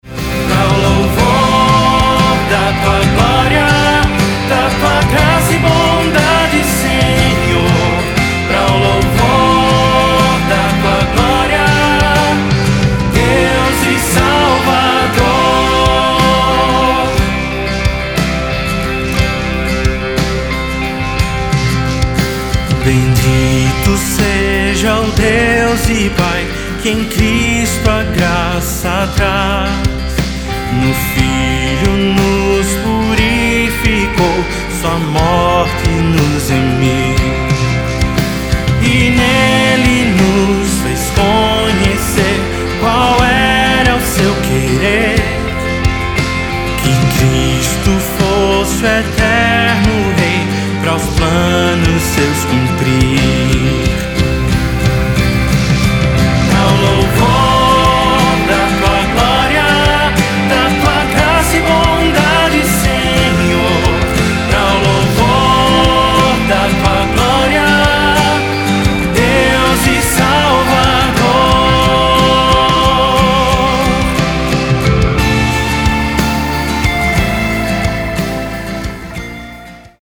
CIFRAS - TOM NO ÁLBUM (A)